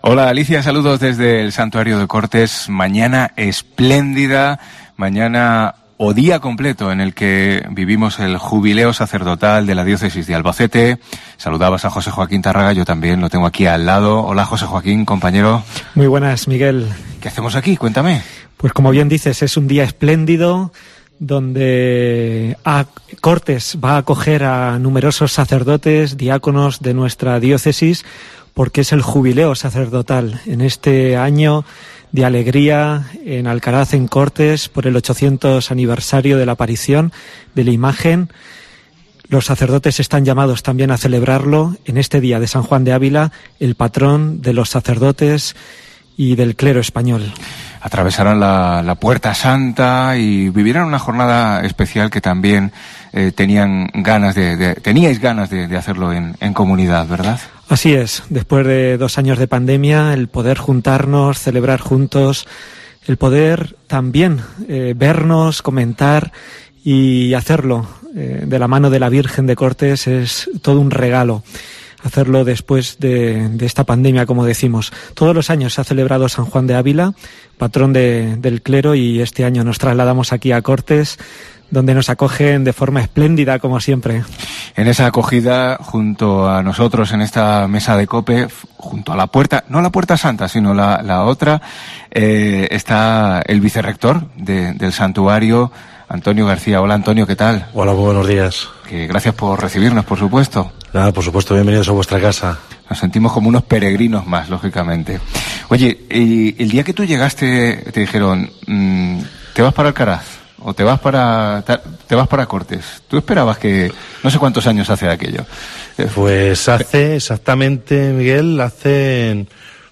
Con tal motivo, la Diócesis de Albacete ha reunido este martes a sus sacerdotes y diáconos en el Santuario de Cortes, desde donde nosotros en COPE hemos realizado nuestra programación del día, desde Herrera en COPE a Mediodía y hasta los informativos de las 14:20 horas.
Especial San Juan de Ávila desde el Santuario de Cortes (1)
También con nosotros el obispo, don Ángel Fernández Collado, que ha atravesado la Puerta Santa del santuario y que a la Virgen de Cortes ha dirigido su oración agradeciendo el trabajo de sus sacerdotes y diáconos, recordándonos lo importante que está siendo este Año Jubilar desde todos los puntos de vista y haciendo una petición a la Madre: "quiero sentirla cerca por que he perdido a mi madre en la Tierra y me refugio en esta Madre del Cielo para que me haga mejor obispo y mejor persona".